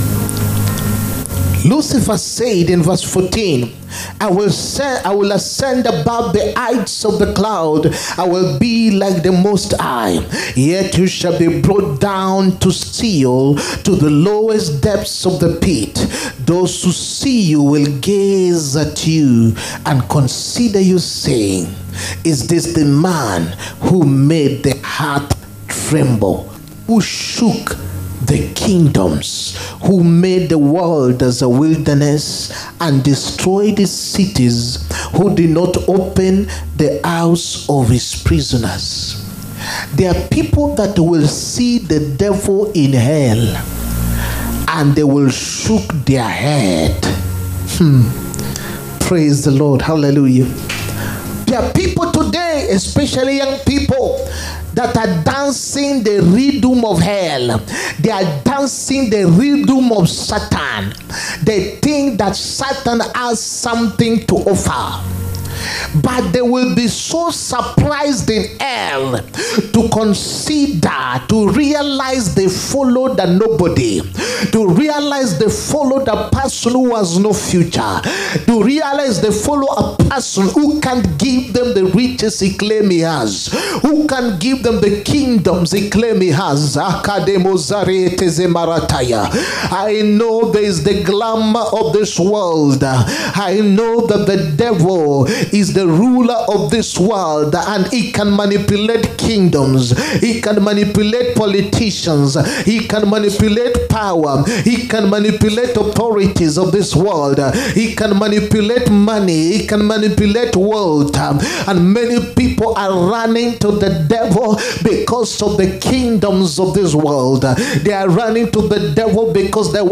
HEALING, PROPHETIC AND DELIVERANCE SERVICE. 7TH SEPTEMBER 2024. PART 2.